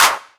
VEC3 Claps 058.wav